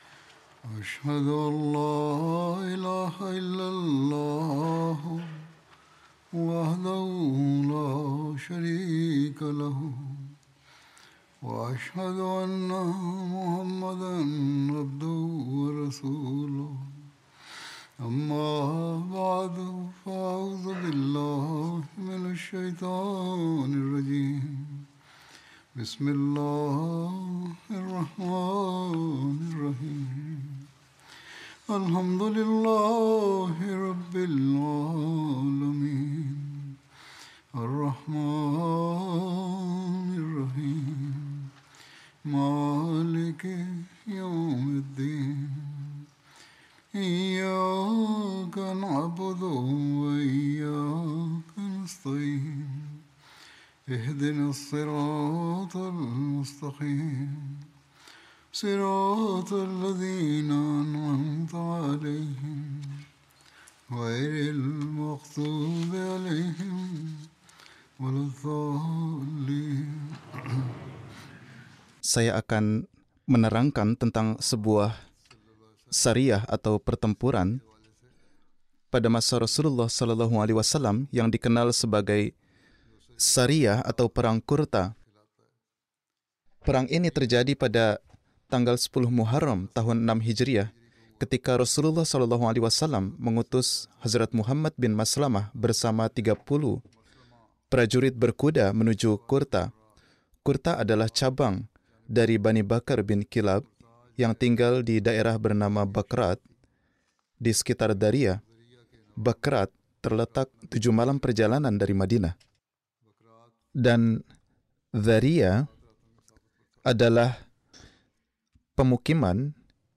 Indonesian Friday Sermon by Head of Ahmadiyya Muslim Community
Indonesian Translation of Friday Sermon delivered by Khalifatul Masih